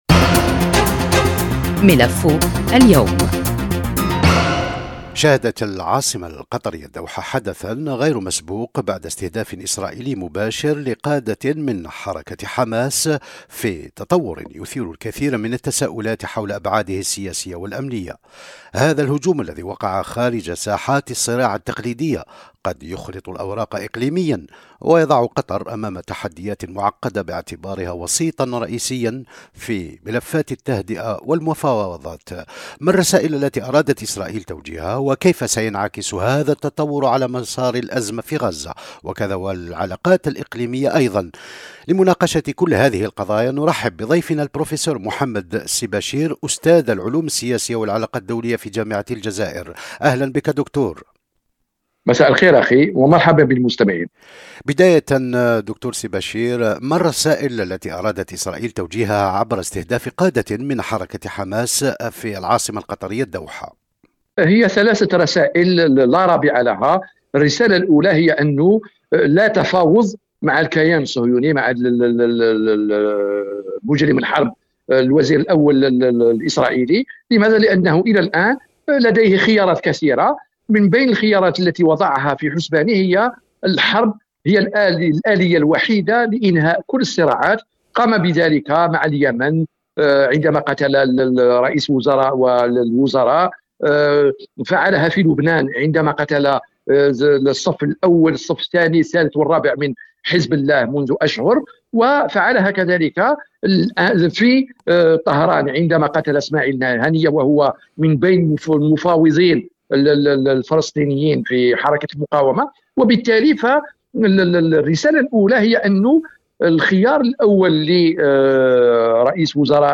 إعداد وتقديم